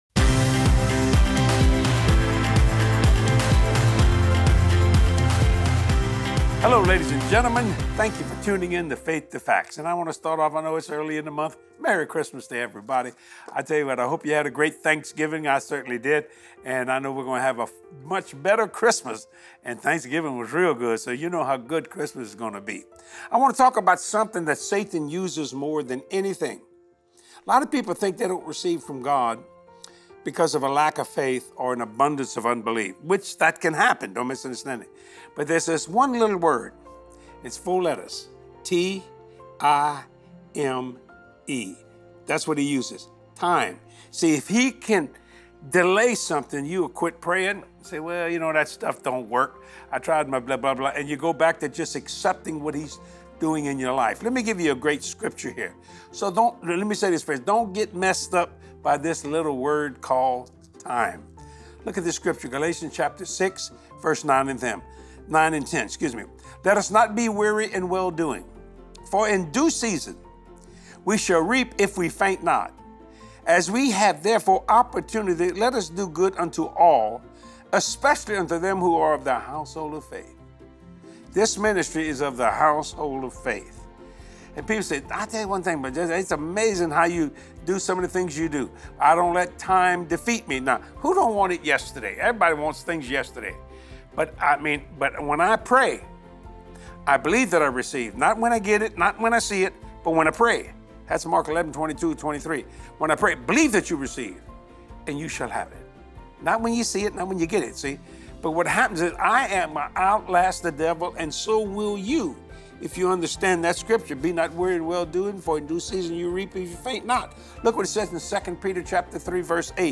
YOU are a timeless being…just like God Almighty! Be inspired to not let time defeat you as you watch this empowering teaching from Jesse.